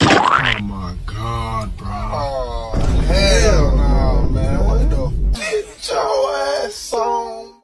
Cartoon Splat Sound Effect 3.mp3